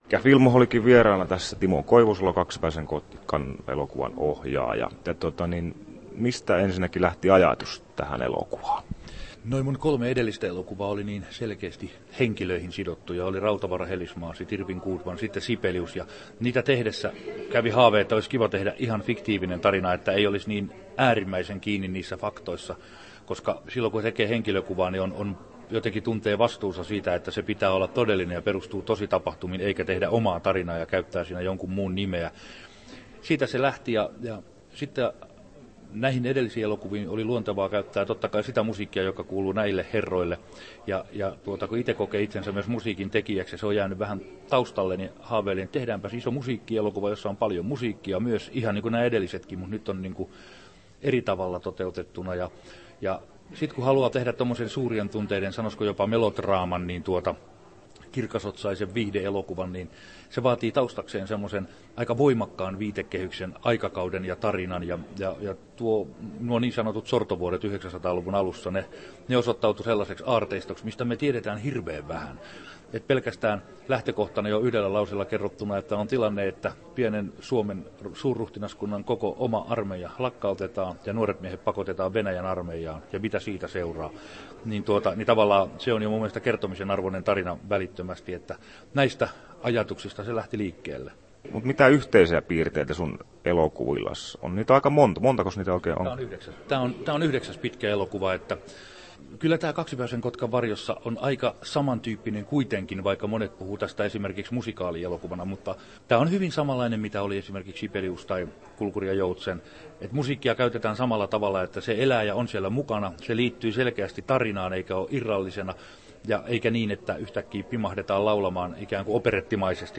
Timo Koivusalo ja Kaksipäisen kotkan varjossa • Haastattelut